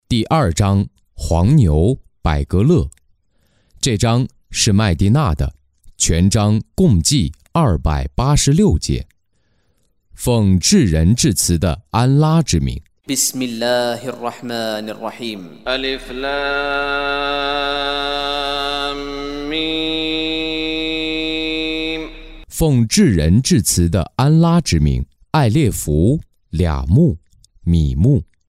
中文语音诵读的《古兰经》第（拜格勒）章经文译解（按节分段），并附有诵经家沙特·舒拉伊姆的朗诵